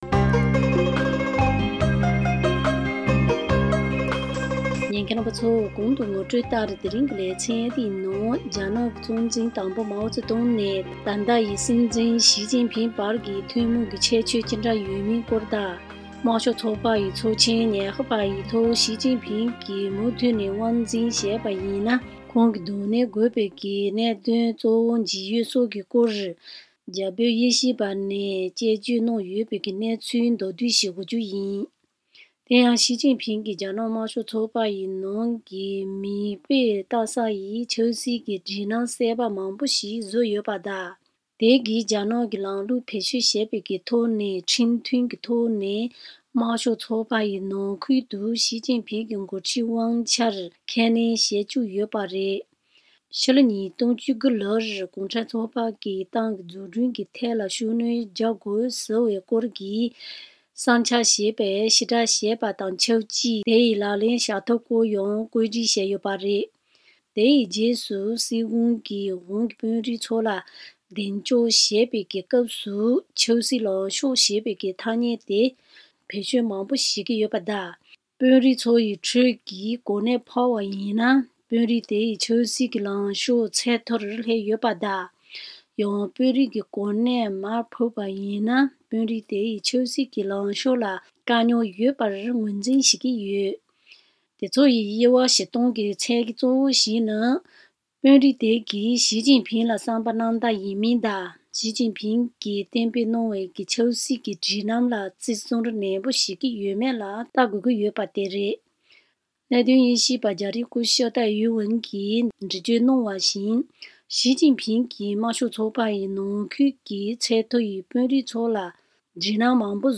རྒྱ་བོད་གནད་དོན་དབྱེ་ཞིབ་པས་འགྲེལ་བརྗོད་གནང་ཡོད་པའི་སྐོར།
གནས་ཚུལ་ཕྱོགས་བསྡུས་ཞུས་པ་ཞིག་གསན་རོགས་ཞུ